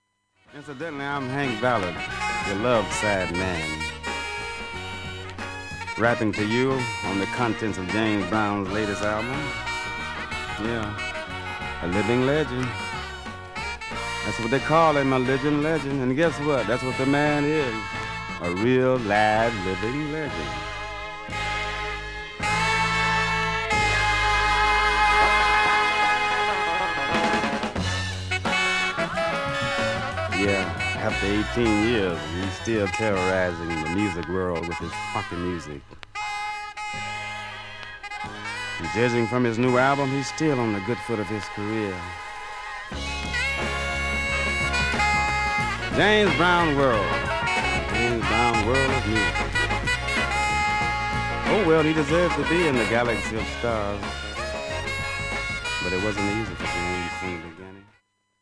クリアな音質
音質良好全曲試聴済み。
B-Boyブレイク、
ファンク・クラシックとして